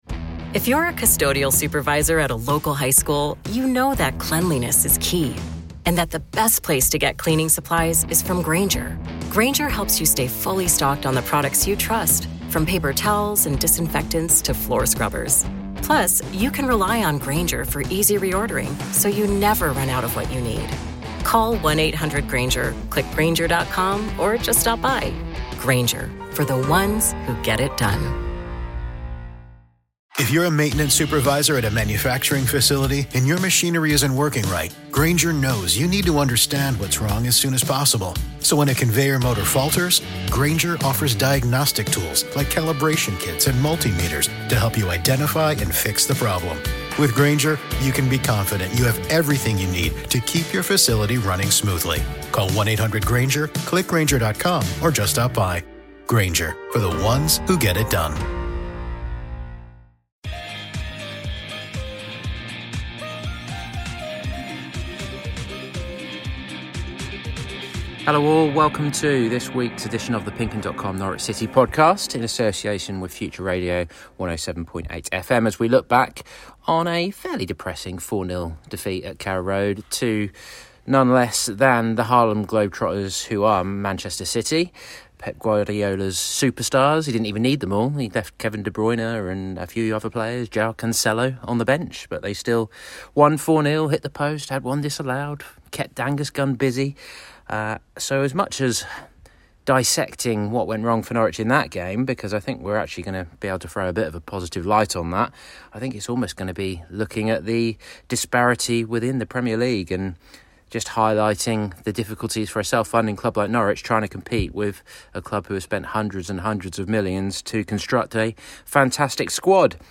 We also bring you audio from our post-match interview with Chelsea loanee Billy Gilmour and start looking ahead to another tough game next weekend, at Liverpool.